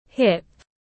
Hông tiếng anh gọi là hip, phiên âm tiếng anh đọc là /hɪp/.
Hip /hɪp/